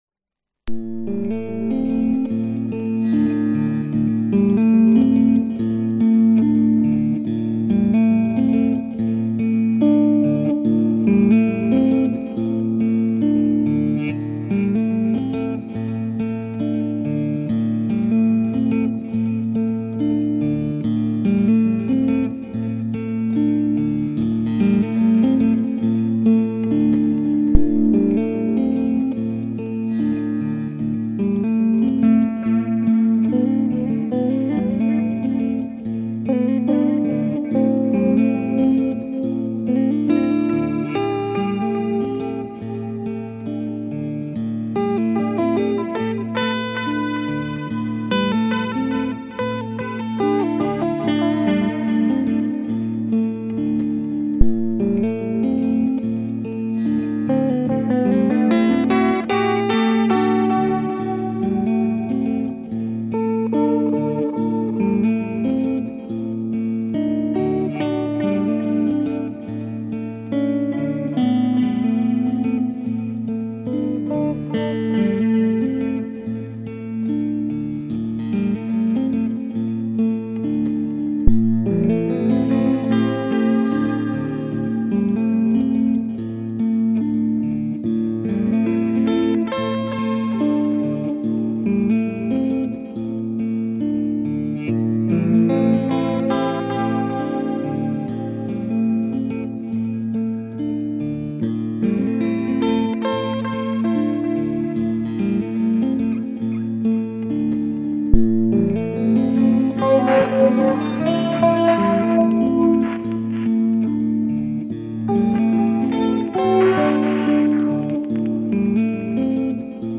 Gitarre solo